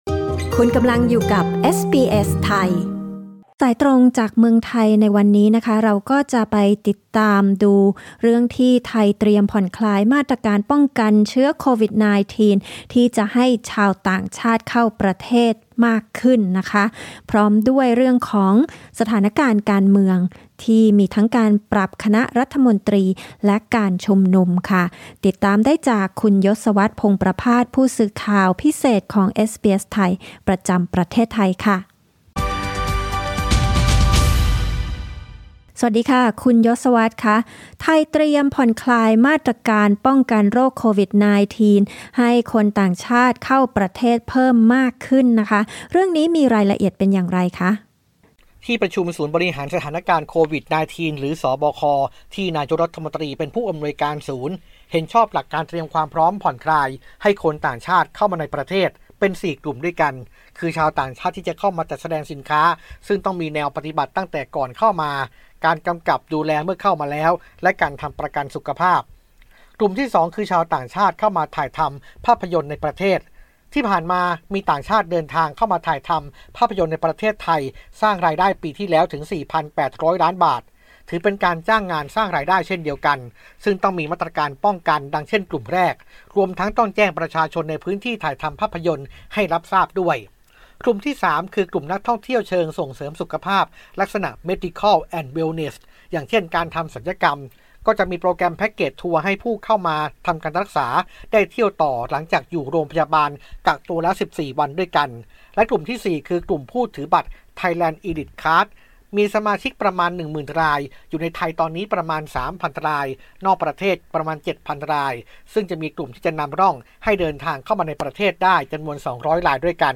รายงานข่าวสายตรงจากเมืองไทย โดย เอสบีเอส ไทย นำเสนอทุกคืนวันพฤหัสบดี Source: Pixabay